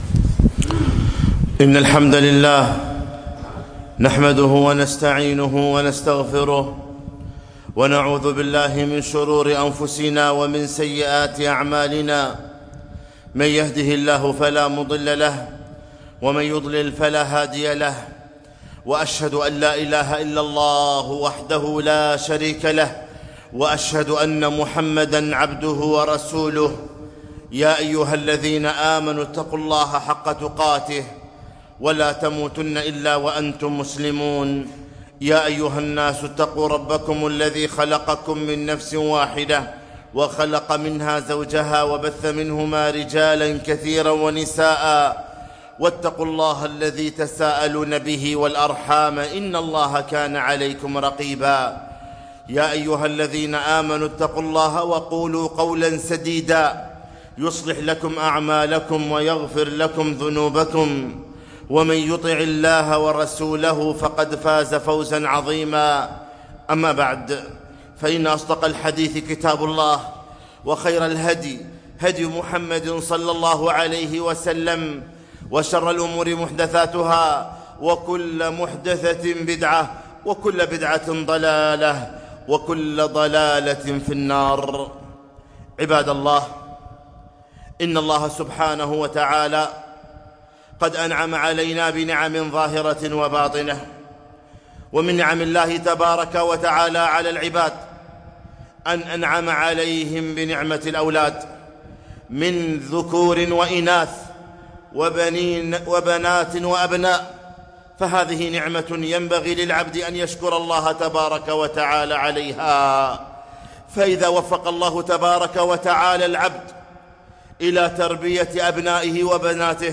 خطبة - لا تضيعوا أولادكم